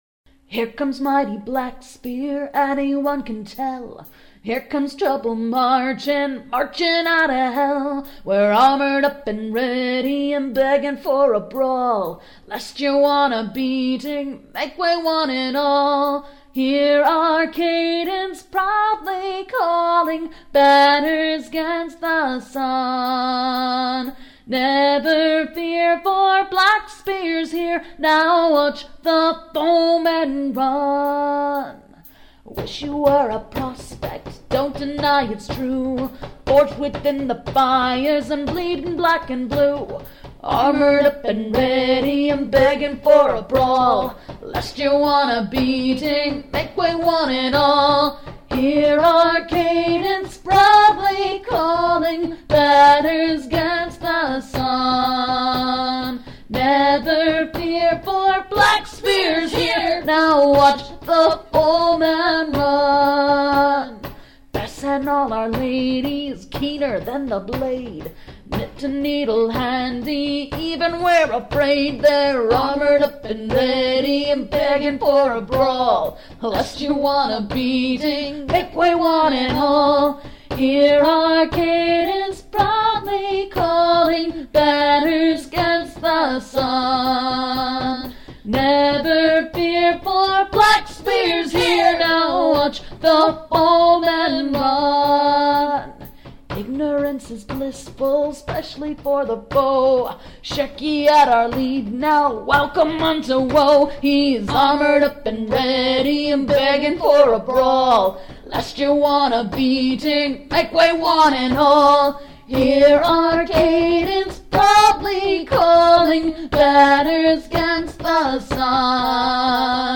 Medieval Folk Music for the Current Middle Ages